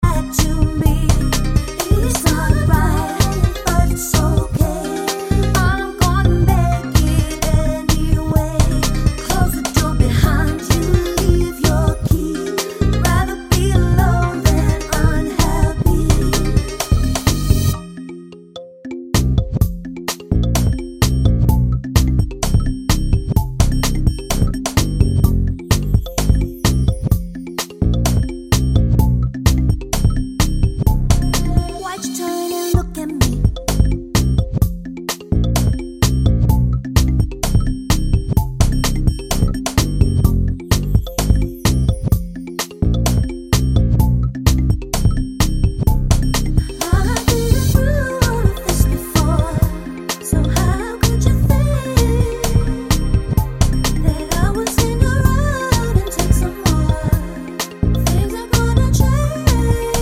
no Backing Vocals R'n'B / Hip Hop 4:12 Buy £1.50